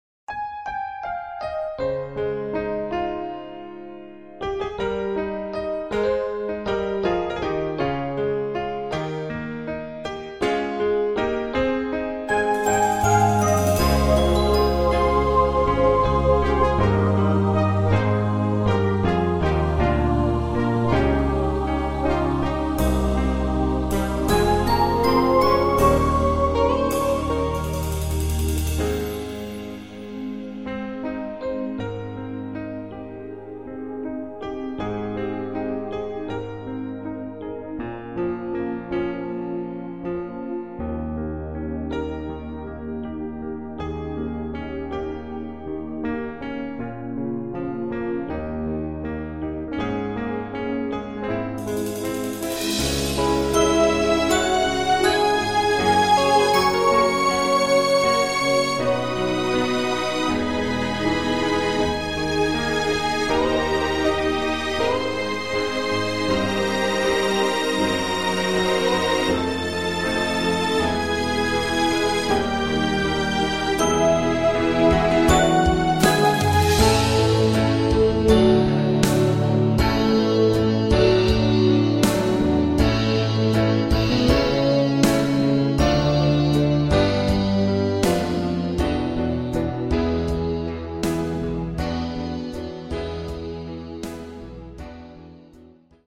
Audio Karaoke